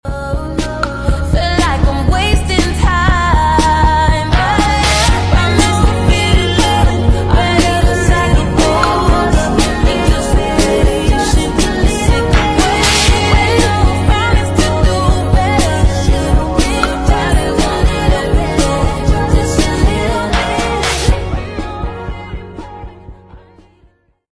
cringe sound effects free download